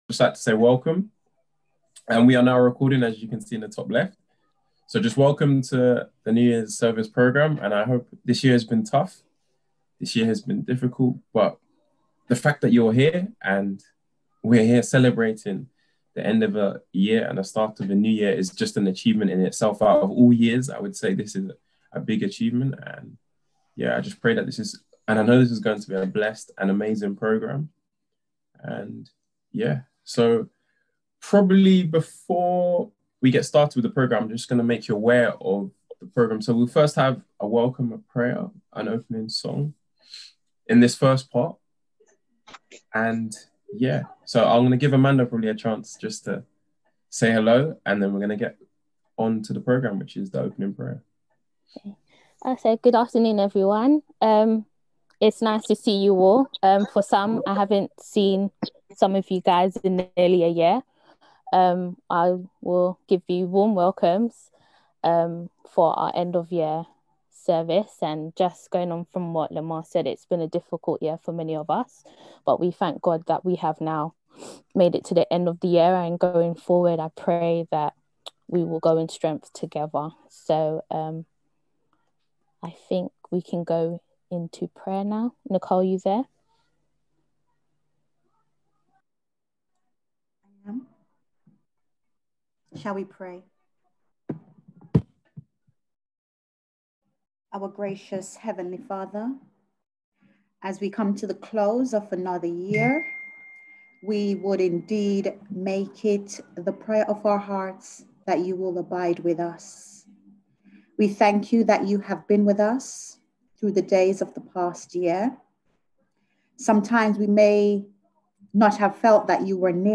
on 2021-01-01 - End of Year Service 31.12.20